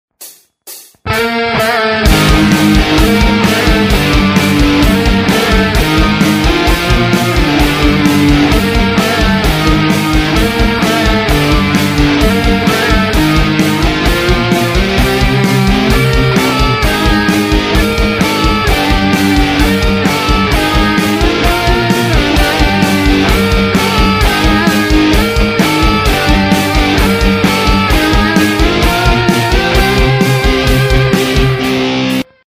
Вниз  Играем на гитаре
решил поигратьсы с многоголосностью и доктором октавиусом. Вообщем идет мелодия, и на левый а потом правый канал, только выше.